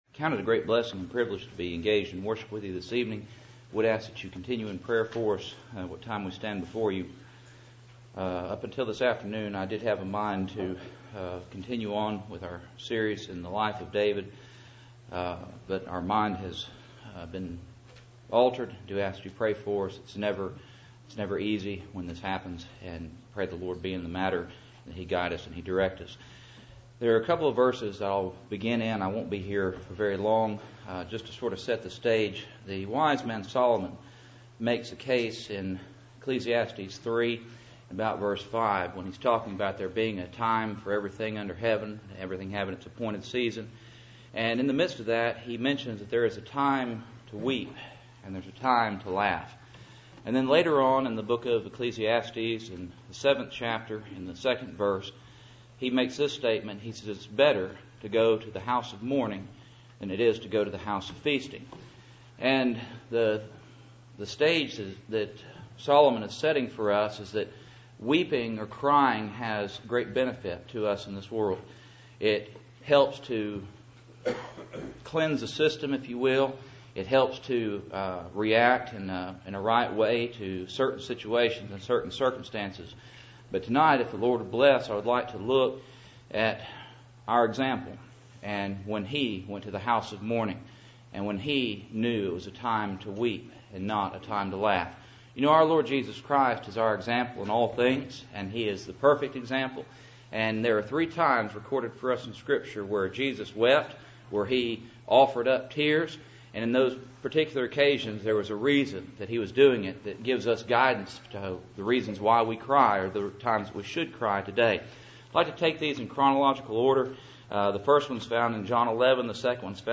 Preacher
Cool Springs PBC Sunday Evening